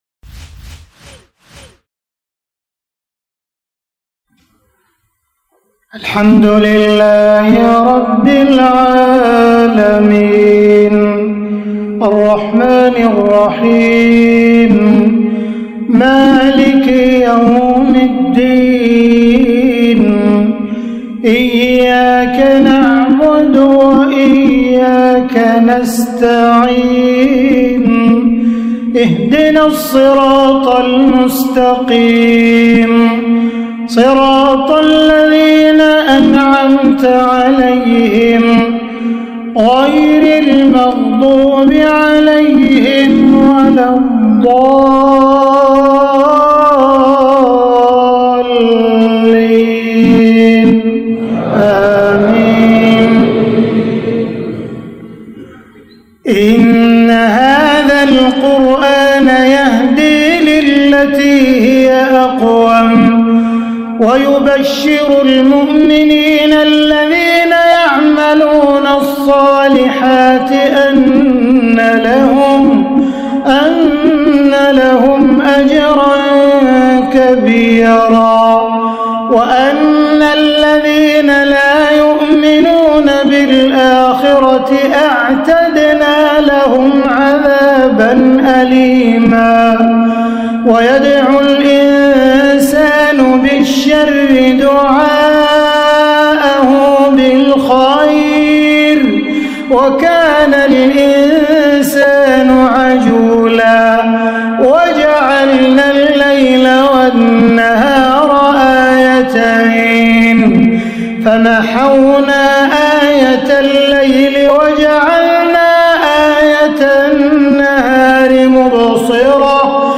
4. مشاركات الشيخ عبدالرحمن السديس خارج الحرم [ تلاوات وكلمات ]